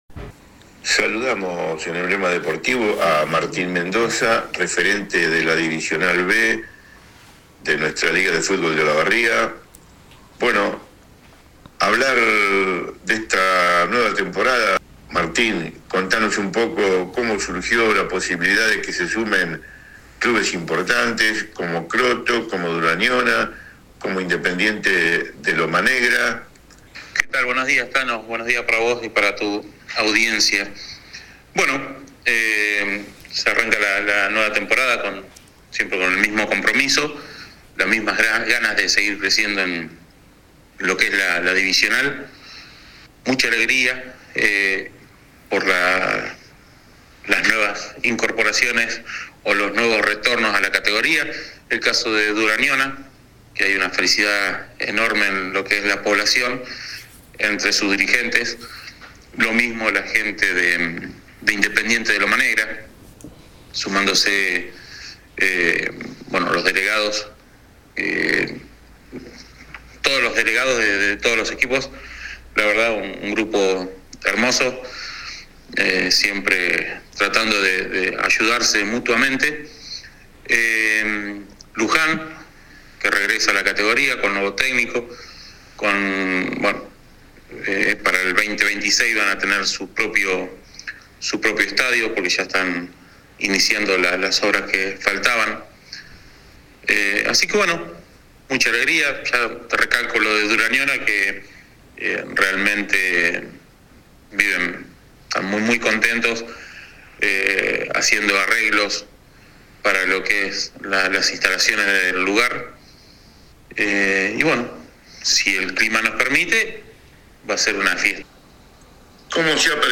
Entrevista al referente de la categoría. Alentadoras novedades para el segundo escalón del fútbol oficial de Olavarría.
AUDIO DE LA ENTREVISTA